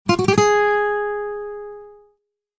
Descarga de Sonidos mp3 Gratis: guitarra 16.